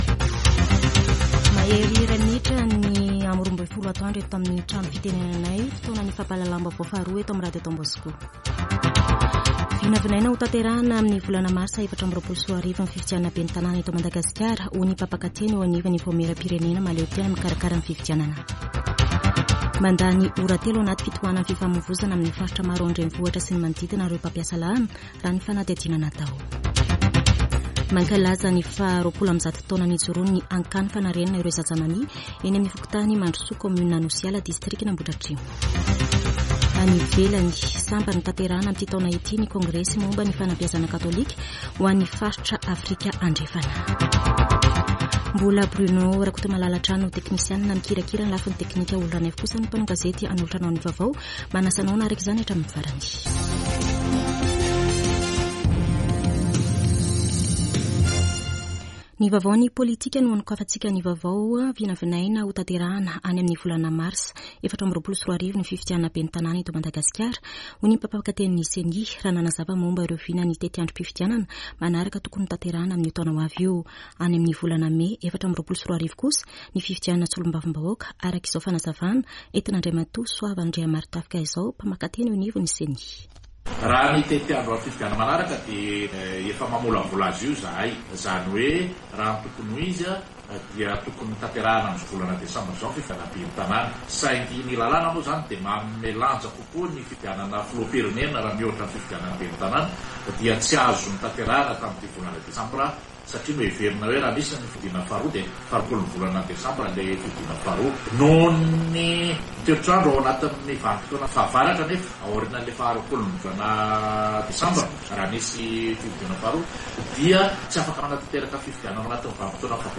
[Vaovao antoandro] Alarobia 13 desambra 2023